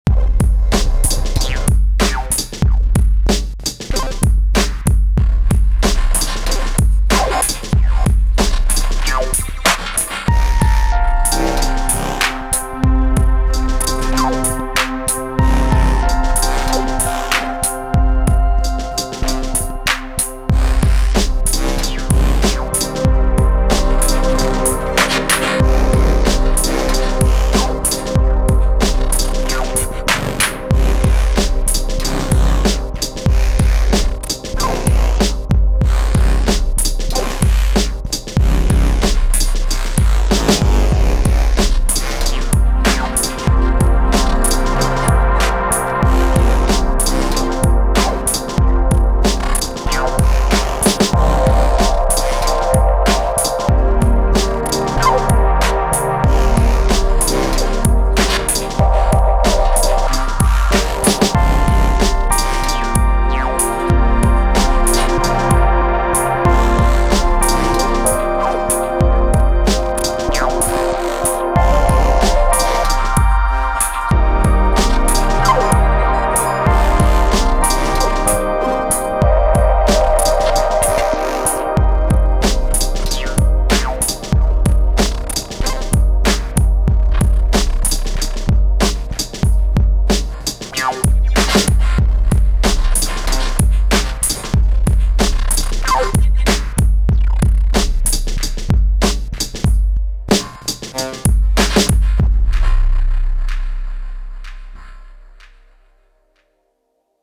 Ran the Syntakt into it, then the Mess into the Digitakt with its side chain gluing the drums & noise together.
The soft melody is the Prophet 08 Rev2, need to work on how to get rougher sounds out of it.
All sequenced off the Squarp Hapax.